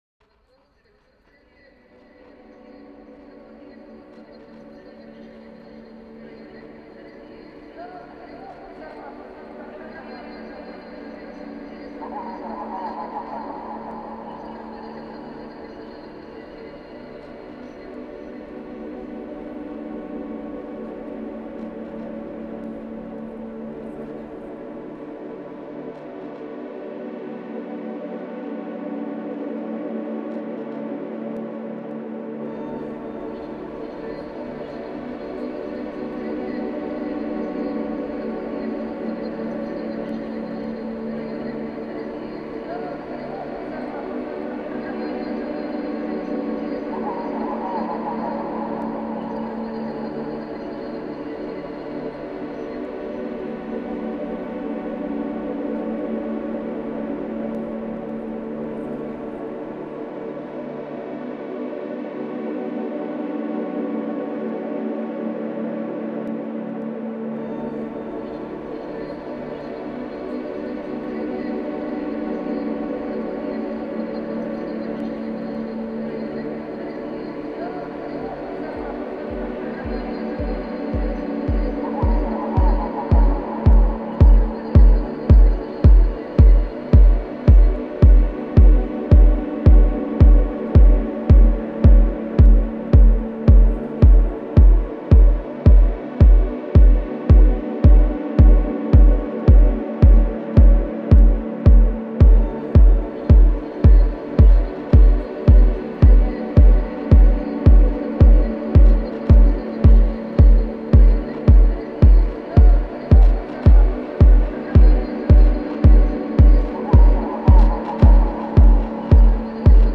Genre: Deep Techno/Ambient/Dub Techno.